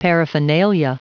Prononciation du mot paraphernalia en anglais (fichier audio)
Prononciation du mot : paraphernalia